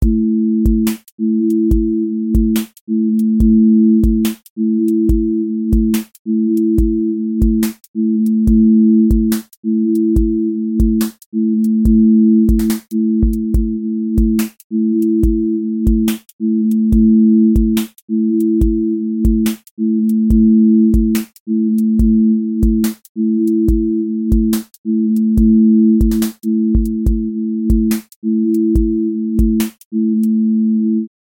QA Listening Test drill Template: drill_glide